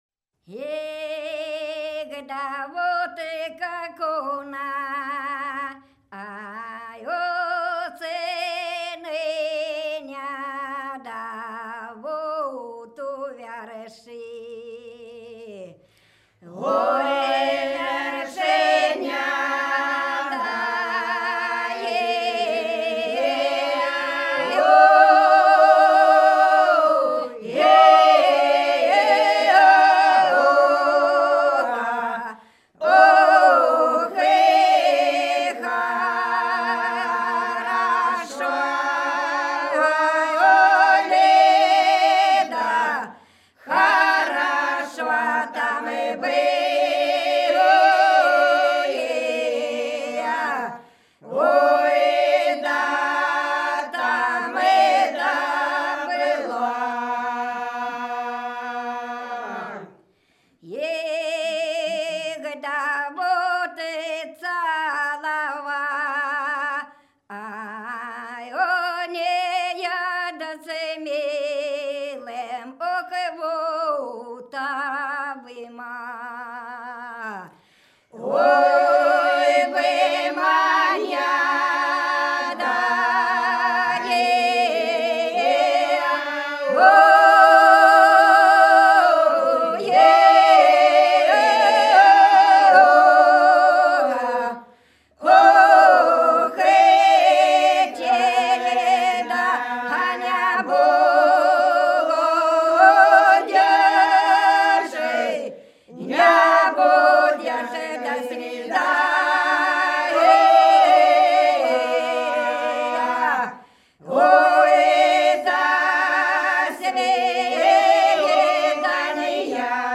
Хороша наша деревня У вершине - протяжная (с. Глуховка)
16_У_вершине_-_протяжная.mp3